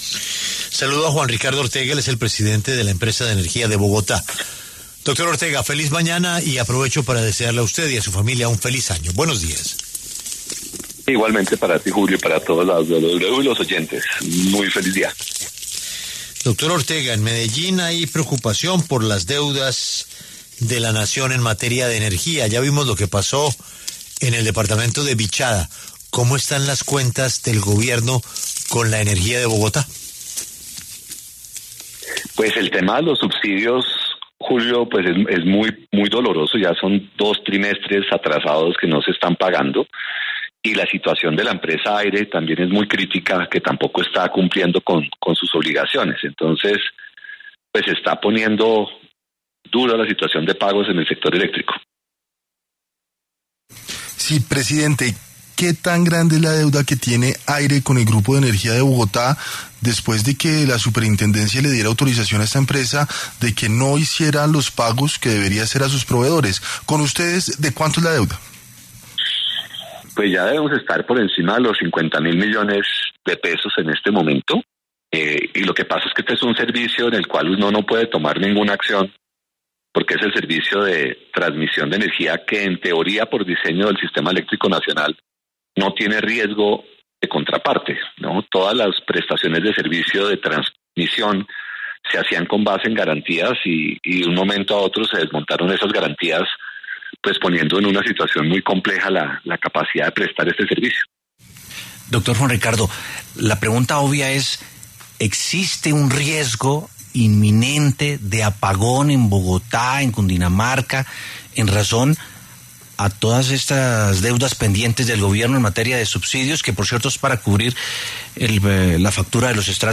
En una reciente entrevista en La W, con Julio Sánchez Cristo, el presidente del Grupo de Energía de Bogotá, Juan Ricardo Ortega, confirmó que la empresa Aire acumula una deuda superior a los 50.000 millones de pesos con el grupo.